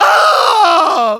Engineer_paincrticialdeath04_de.wav